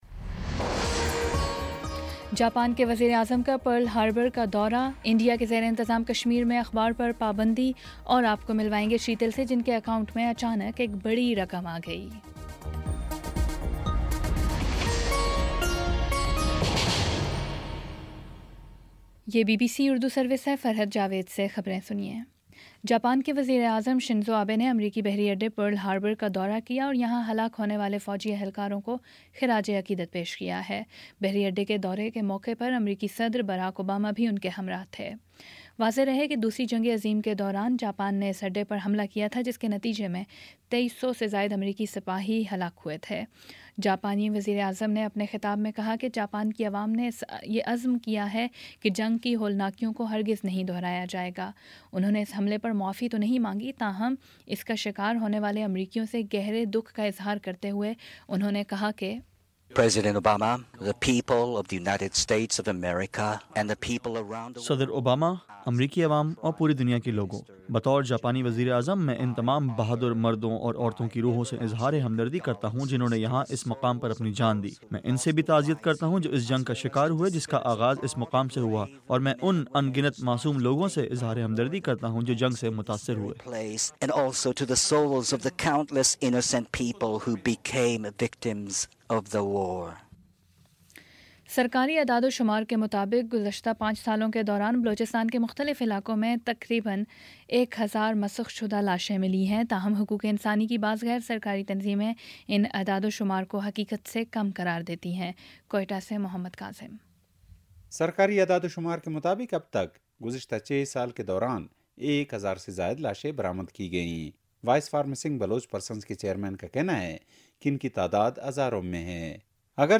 دسمبر 28 : شام چھ بجے کا نیوز بُلیٹن